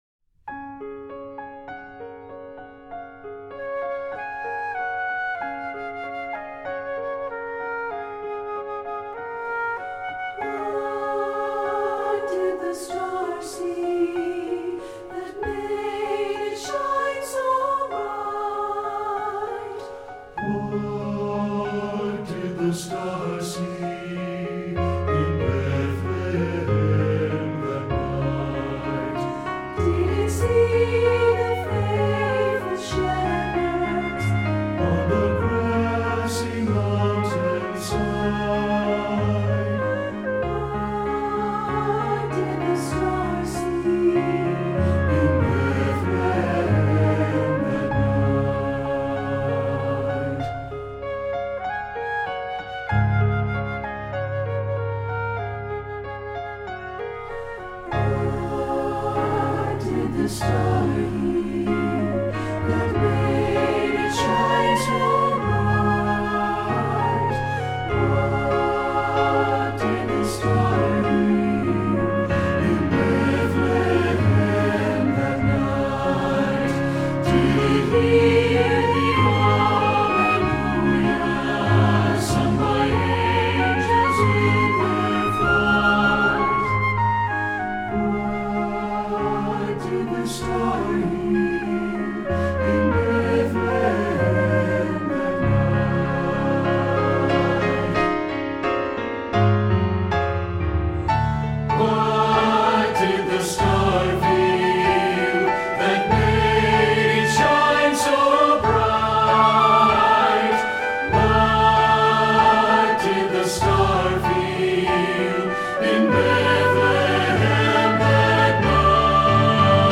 Voicing: SATB, Flute and Children's Choir